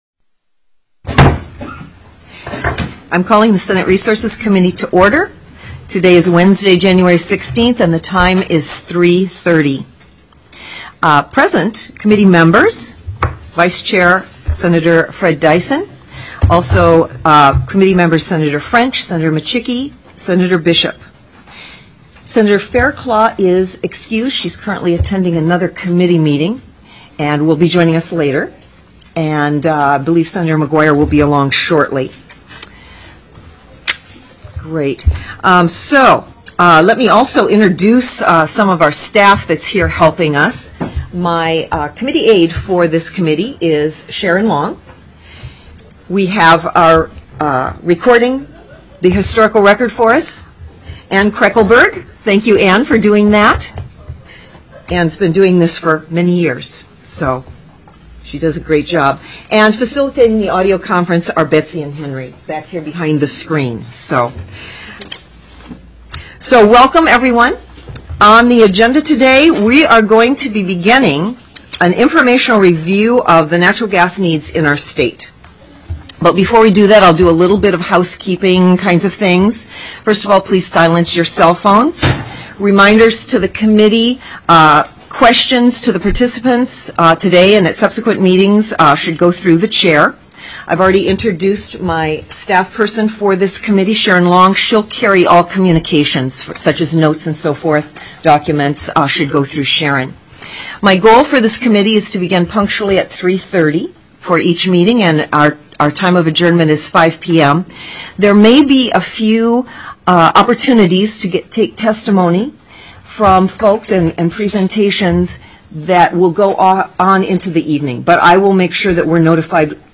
01/16/2013 03:30 PM Senate RESOURCES
ACTION NARRATIVE 3:29:59 PM CHAIR CATHY GIESSEL called the Senate Resources Standing Committee meeting to order at 3:29 p.m. Present at the call to order were Senators Dyson, Bishop, French, Micciche and Chair Giessel.